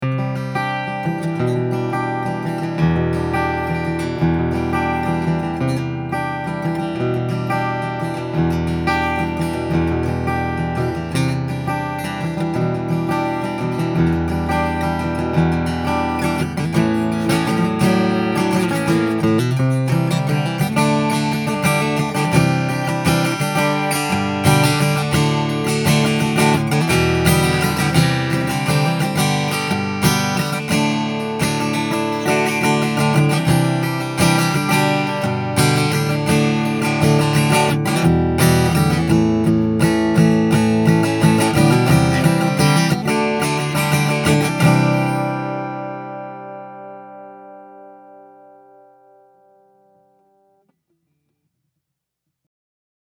All of the clips are with the guitar plugged directly into my pre-amp going into my DAW.
I recorded the individual images with the image mix cranked all the way up.